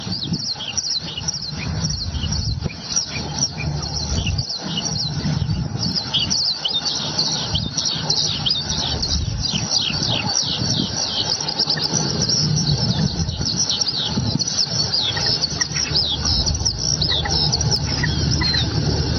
野外捕捉到的小云雀叫声